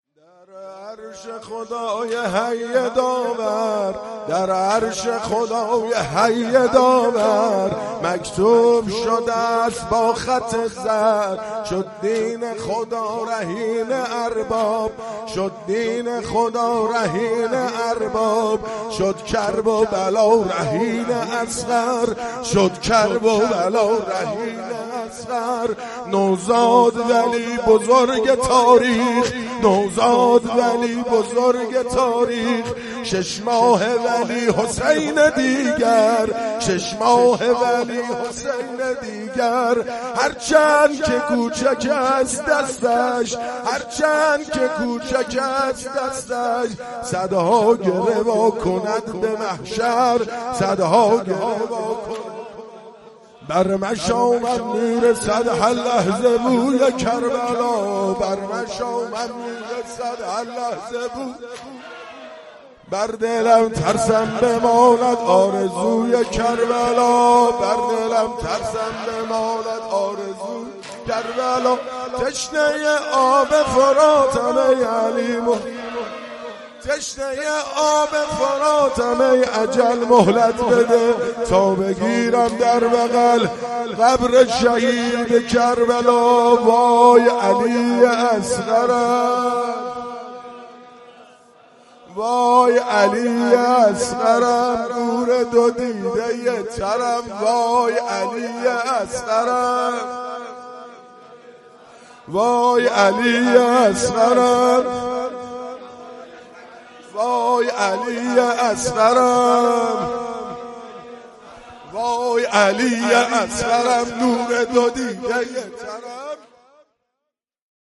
مداحی شب هفتم محرم 98 ( واحد سوم )